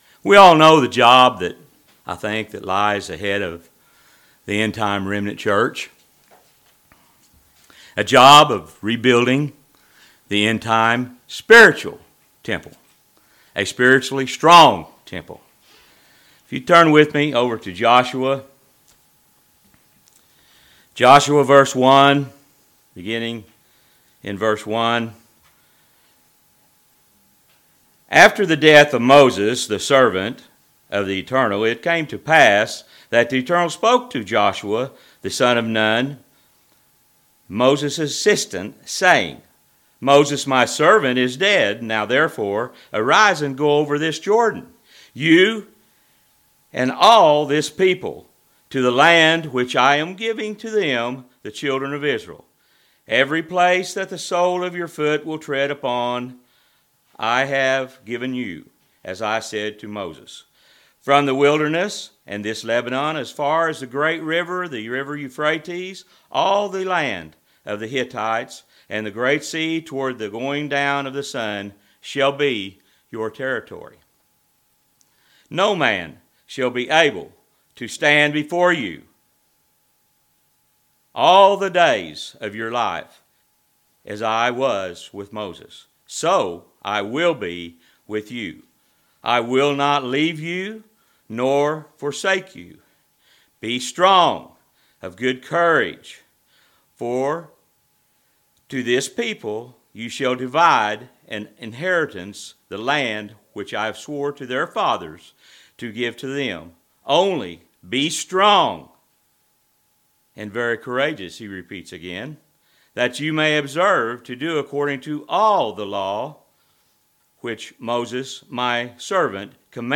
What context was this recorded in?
Given in Knoxville, TN